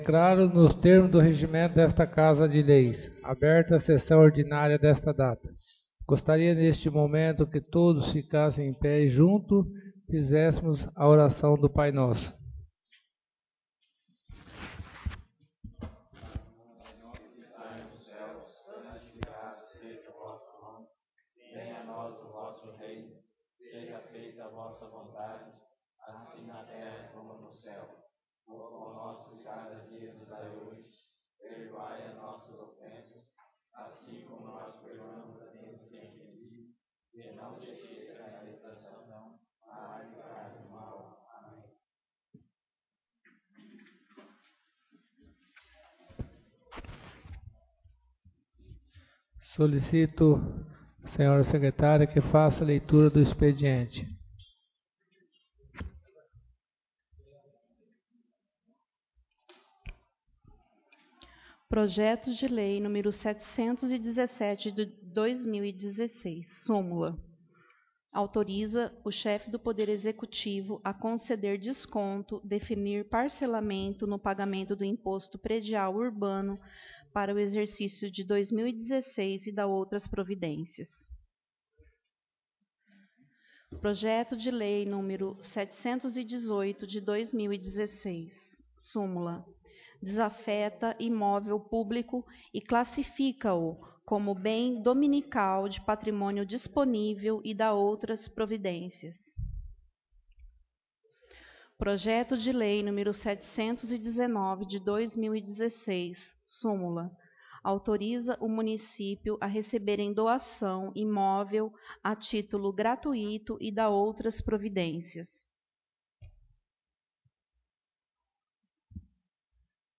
Sessão 07/03/2016 — CÂMARA MUNICIPAL DE NOVA SANTA HELENA - MT